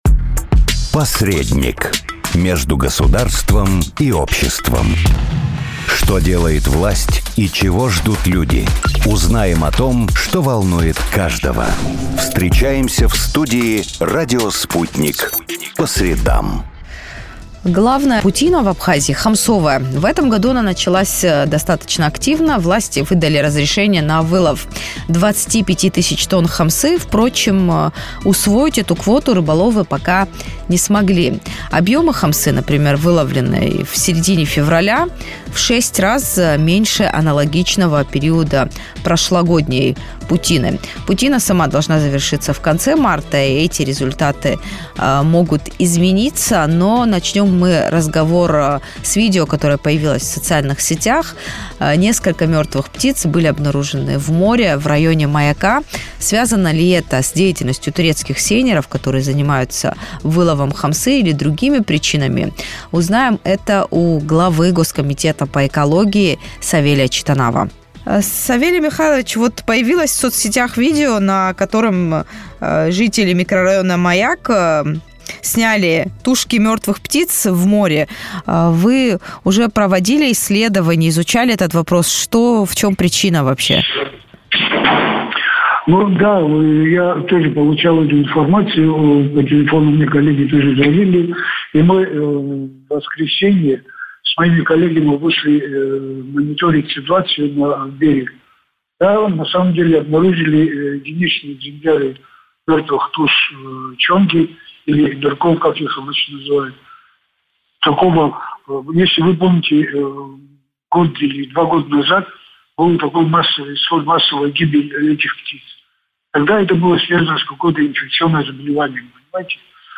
Глава Госкомитета по экологии Савелий Читанава в интервью радио Sputnik рассказал о первоочередных задачах, стоящих перед ведомством весной.